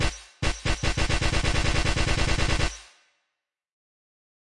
描述：使用来自freesound.Far距离声音的声音重新创建光环
Tag: 科幻 卤素 刺针